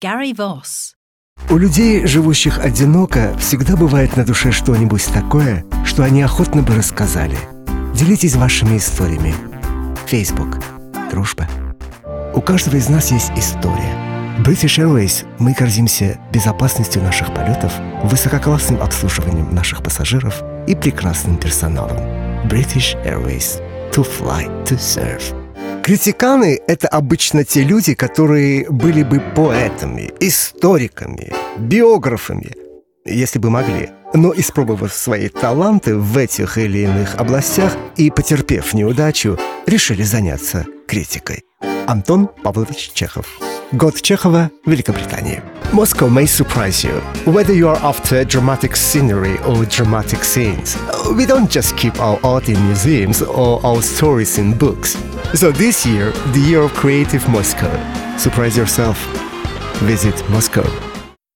Russian actor and voiceover artist